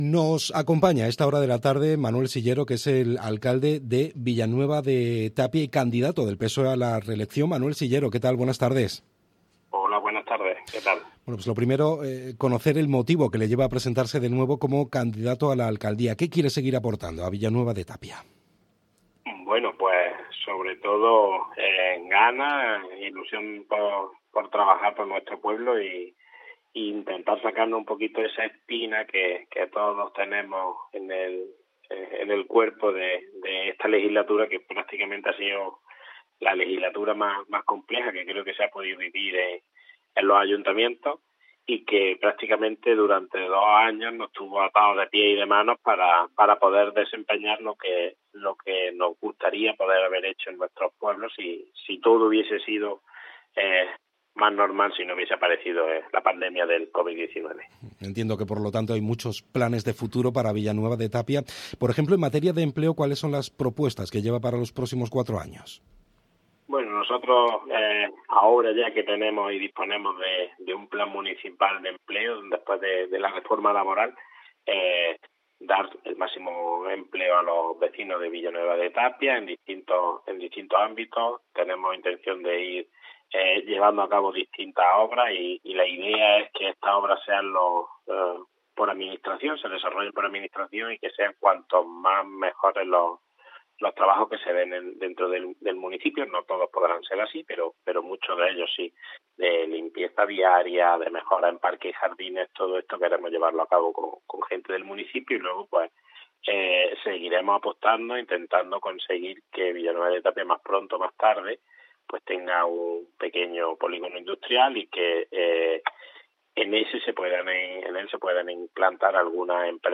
Entrevista 28M